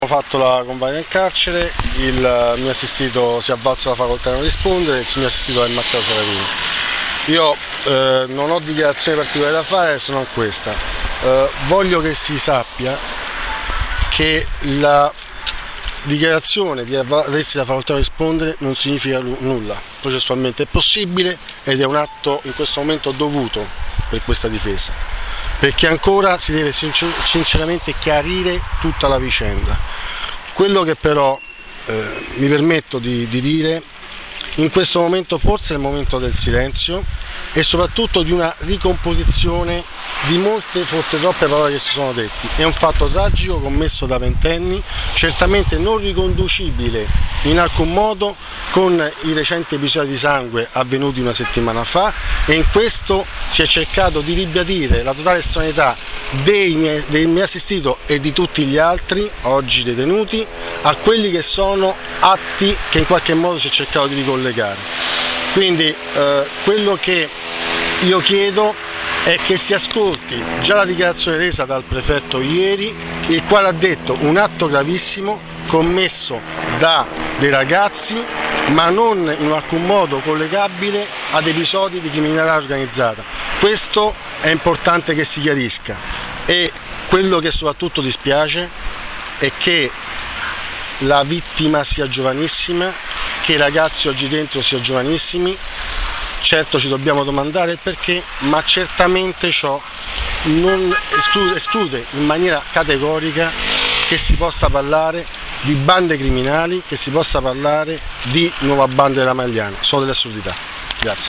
Per i dettagli sulle dichiarazioni ascoltare le interviste agli avvocati difensori rilasciate subito dopo l’interrogatorio:
L’intervista